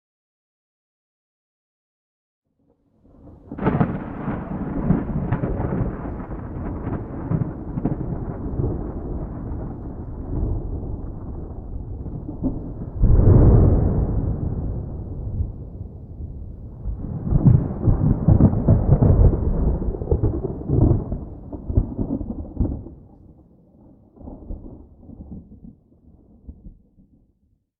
thunderfar_2.ogg